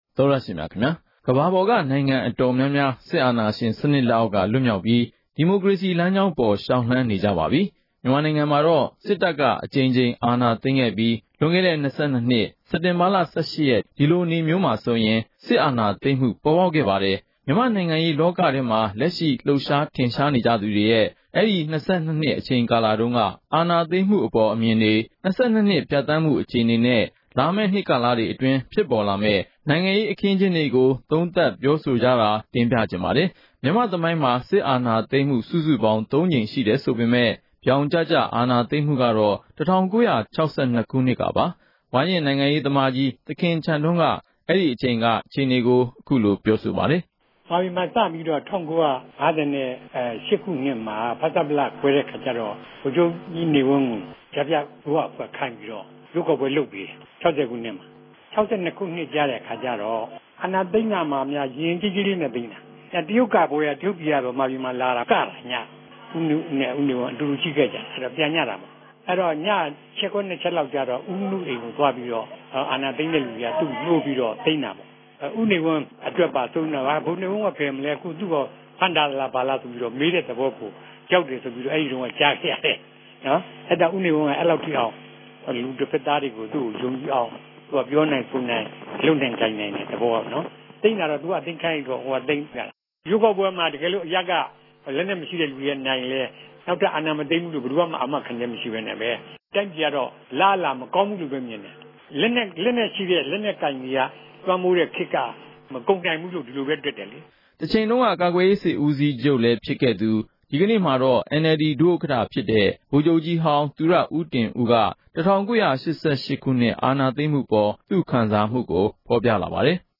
မြန်မာနိုင်ငံမှာ တပ်မတော်က ၁၉၆၂ ခုနှစ်မှာတကြိမ်၊ ၁၉၈၈ ခုနှစ်မှ ာတကြိမ် ဆက်တိုက်ဆိုသလို စစ်အာဏာသိမ်းပွဲတွေ လုပ်ခဲ့ပါတယ်။ အဲဒီကာလတွေ အားလုံးကို ဖြတ်သန်းခဲ့ကြဖူးတဲ့ ဝါရင့် နိုင်ငံသမားတွေ၊ စစ်တပ် ခေါင်းဆောင်ဟောင်းတွေ၊ တိုင်းရင်းသားခေါင်းဆောင်တွေက စစ်အာဏာသိမ်းမှုနဲ့ ပတ်သက်ပြီး သူတို့ရဲ့ အမြင်အမျိုးမျိုးကို မှတ်ချက်ပေး ပြောဆိုကြပါတယ်။